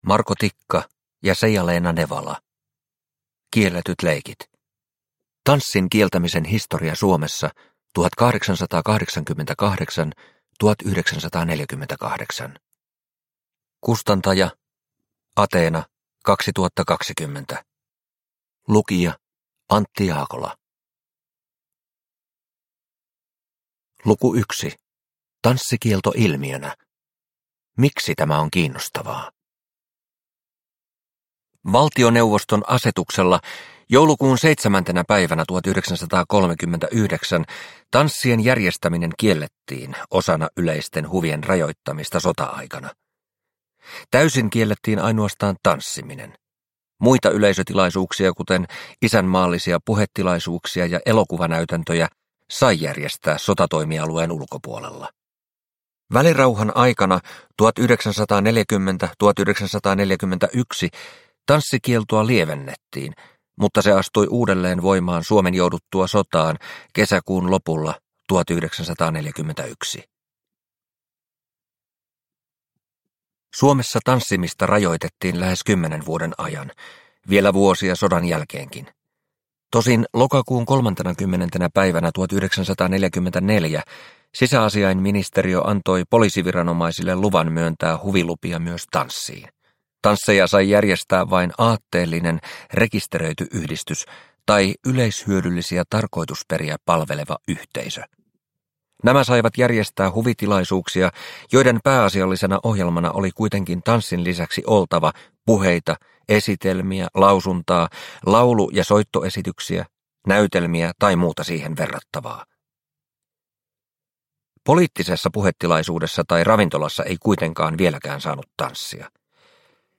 Kielletyt leikit – Ljudbok – Laddas ner